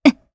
Xerah-Vox_Damage_jp_01.wav